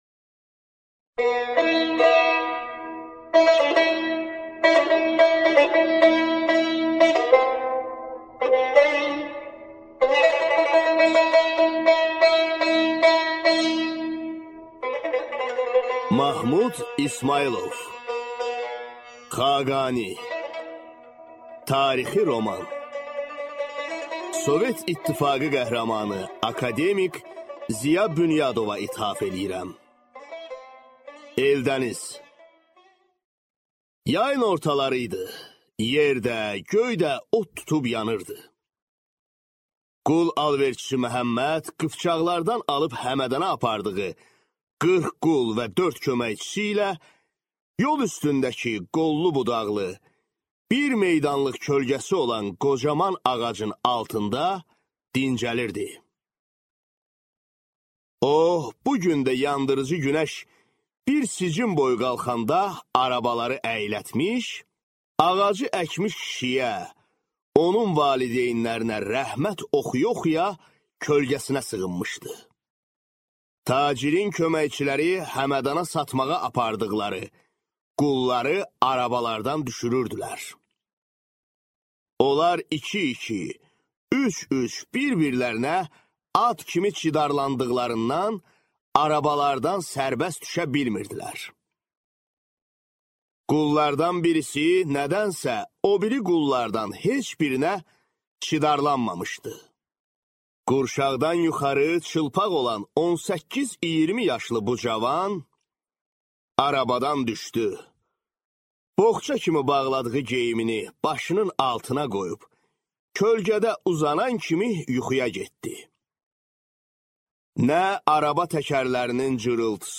Аудиокнига Xaqani | Библиотека аудиокниг